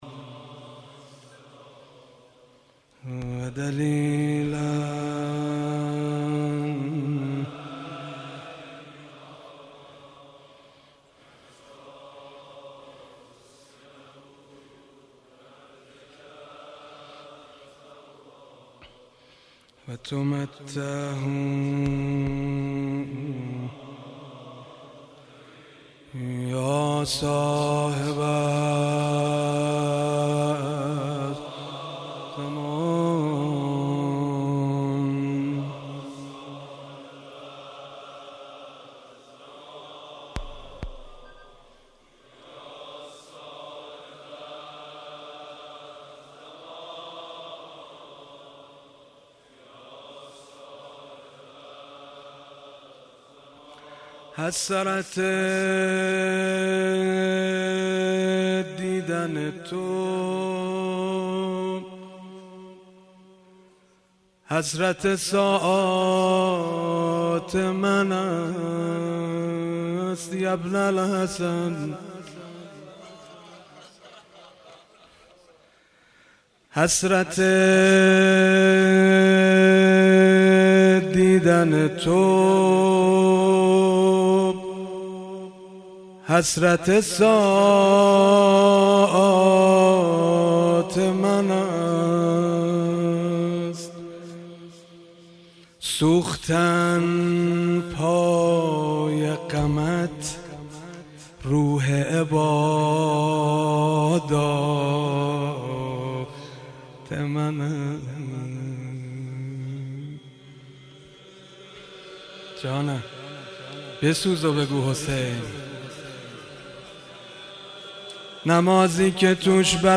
مجموعه نوحه های شهادت حضرت مسلم علیه السلام
در هیئت بین الحرمین تهران اجرا شده است